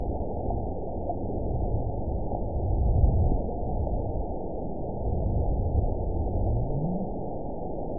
event 917331 date 03/27/23 time 23:25:19 GMT (2 years, 1 month ago) score 9.55 location TSS-AB01 detected by nrw target species NRW annotations +NRW Spectrogram: Frequency (kHz) vs. Time (s) audio not available .wav